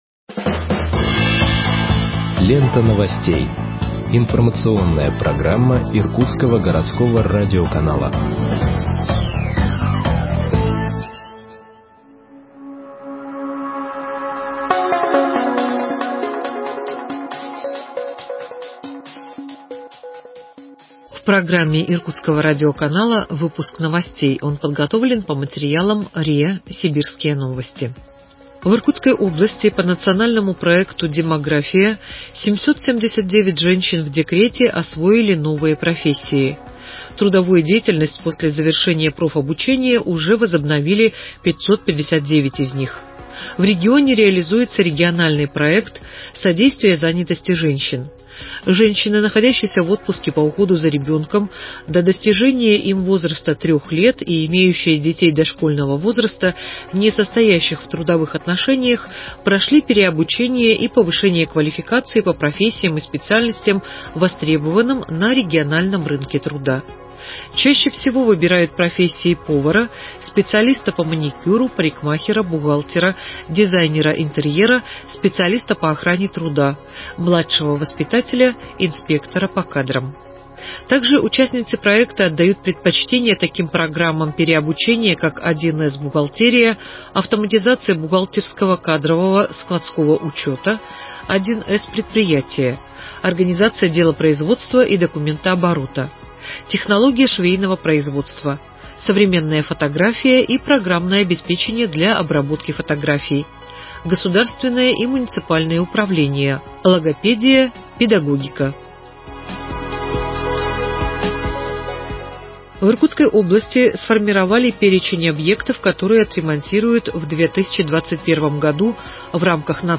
Выпуск новостей в подкастах газеты Иркутск от 15.12.2020 № 1